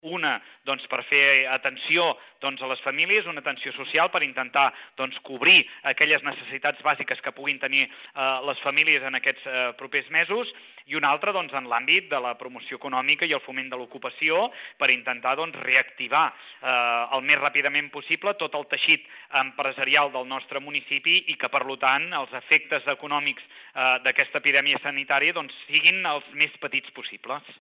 El pla de xoc preveu 12 accions concretes, que es divideixen en dos apartats, tal com explica l’alcalde Lluís Puig a Ràdio Palamós.